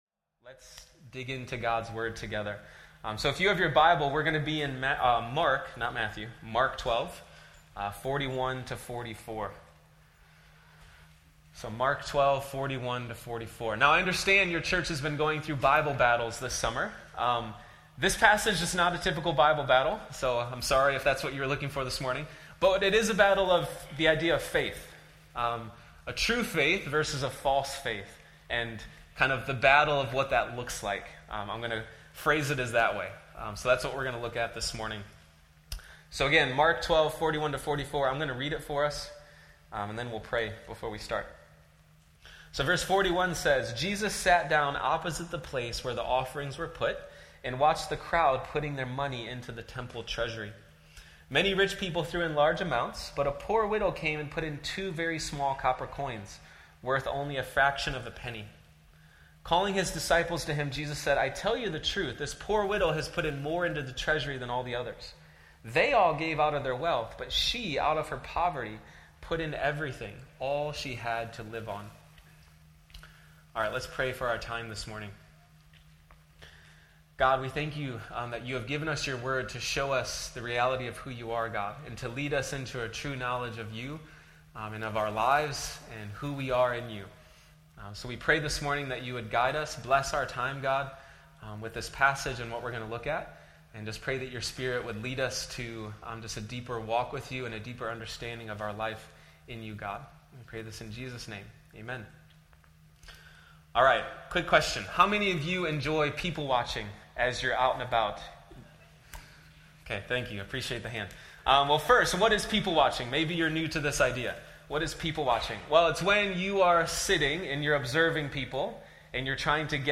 sermon_audio_mixdown_8_17_25.mp3